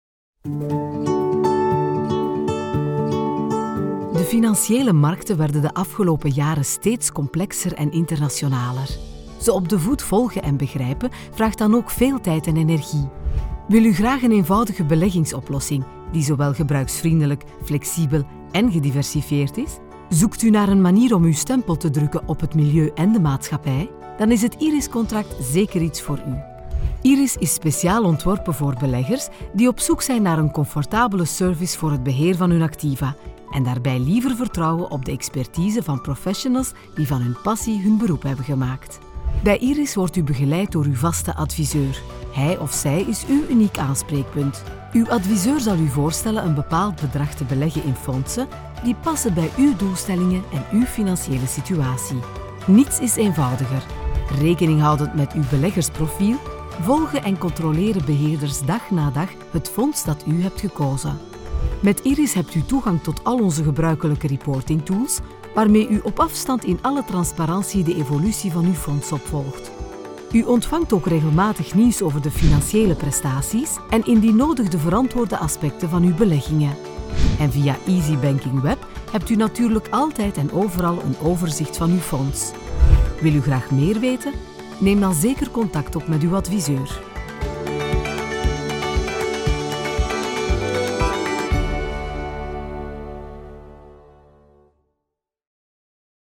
Profundo, Natural, Seguro, Amable, Cálida
Corporativo